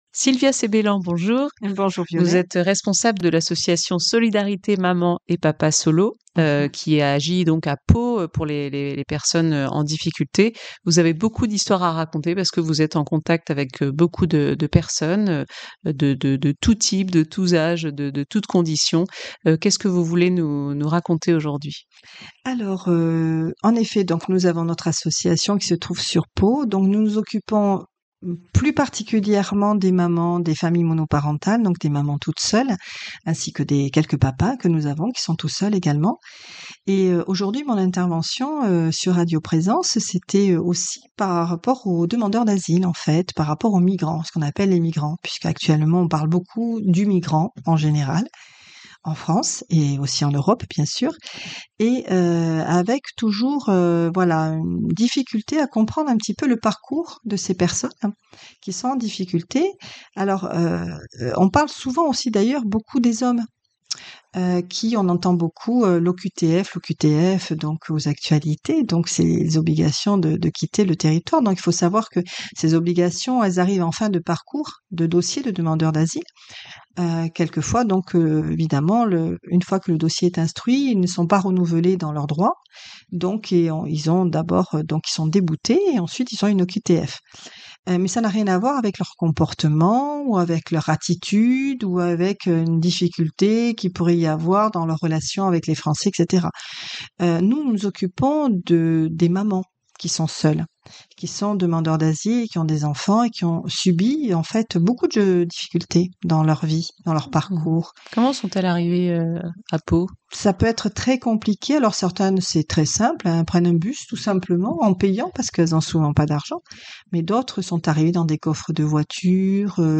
Témoignage : une association au contact des mamans migrantes